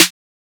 Digital Dash Snare.wav